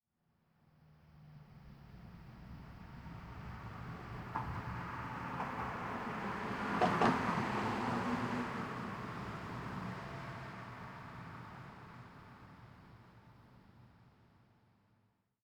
1Shot Vehicle Passby with Tire Bumps ST450 04_ambiX.wav